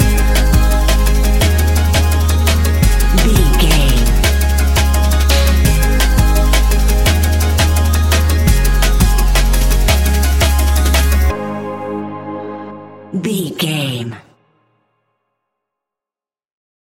Ionian/Major
G♯
electronic
techno
trance
synths
synthwave